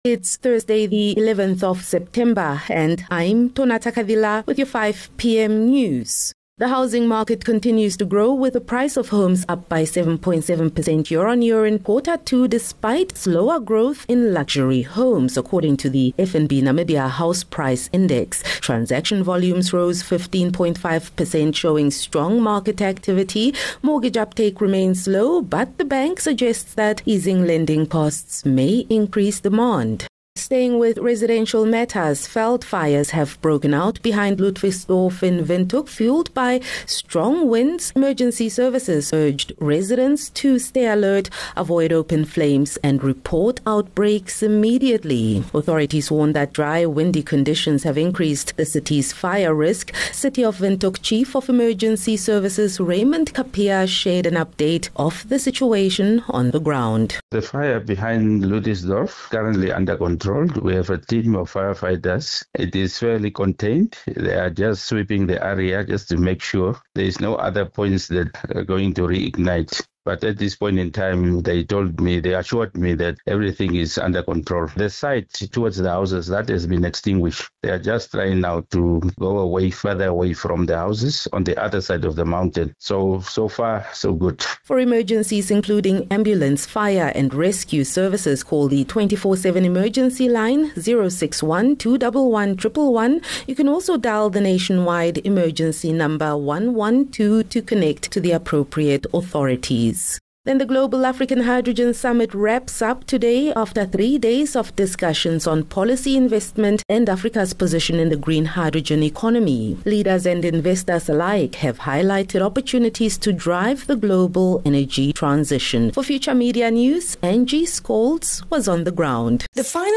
11 Sep 11 September - 5 pm news